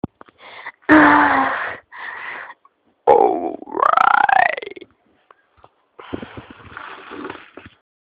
Play the Aaa ou right sound button instantly. 8-second meme soundboard clip — free, in-browser, no signup, no download required.
A vocal exclamation popular online, possibly said with a regional accent. Use it as a quick reaction or expression sound.